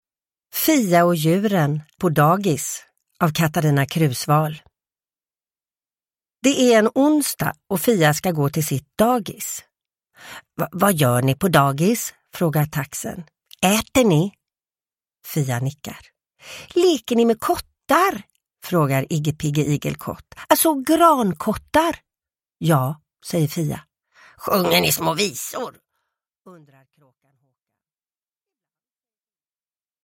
Fia och djuren på dagis – Ljudbok – Laddas ner
Uppläsare: Sissela Kyle